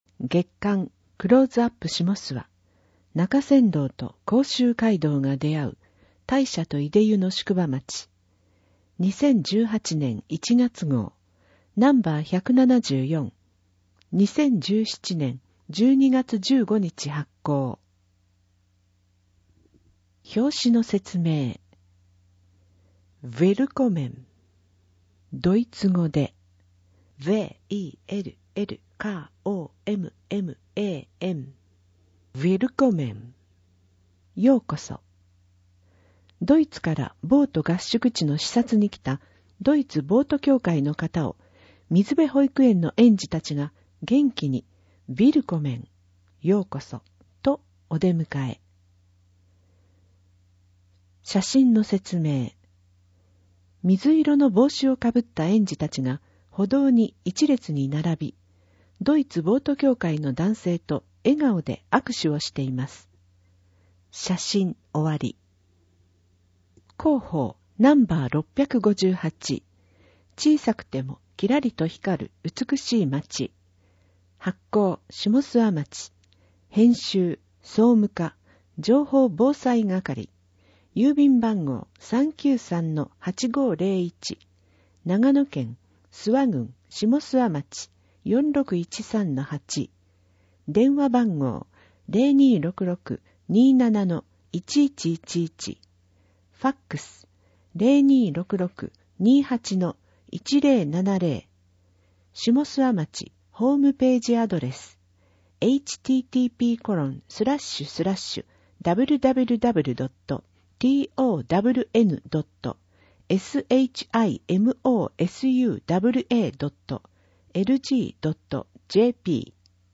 ダウンロード （音読版）クローズアップしもすわ2018年1月号 [ mp3 type：19MB ] （音読版）生涯学習524号 [ mp3 type：5MB ] 添付資料を見るためにはビューワソフトが必要な場合があります。